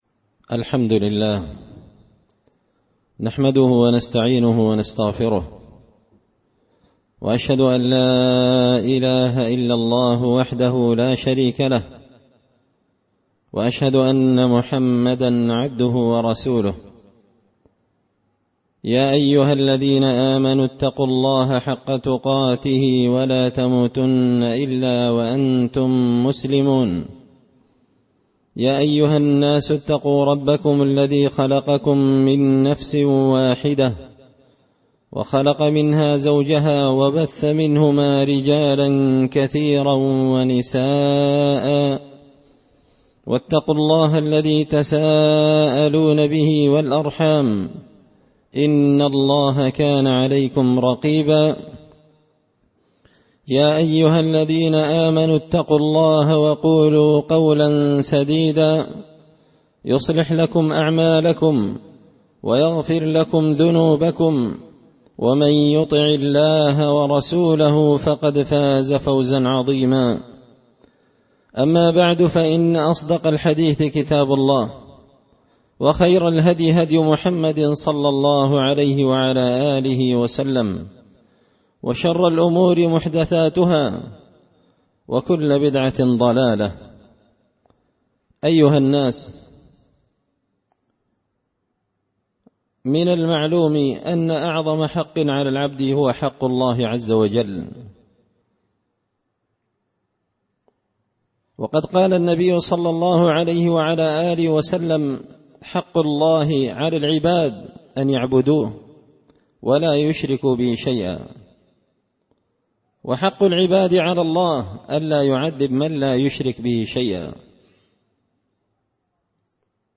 خطبة جمعة بعنوان – حق الله تعالى الجزء الثاني
دار الحديث بمسجد الفرقان ـ قشن ـ المهرة ـ اليمن